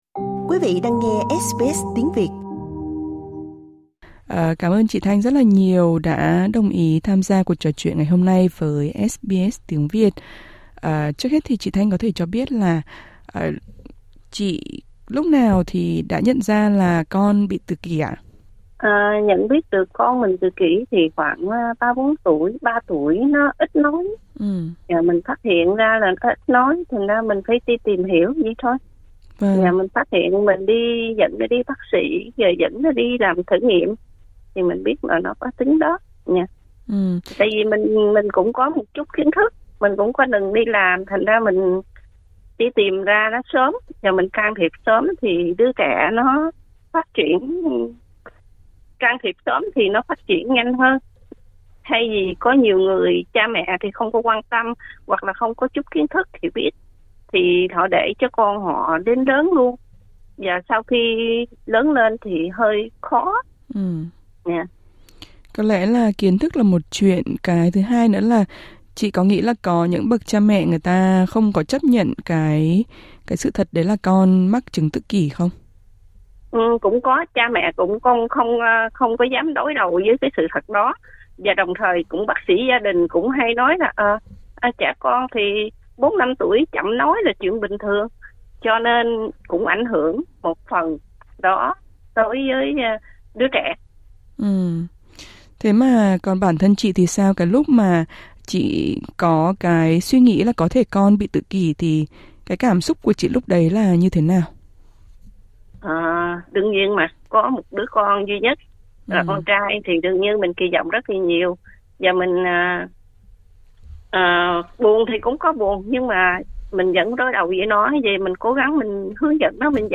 Trò chuyện với SBS Tiếng Việt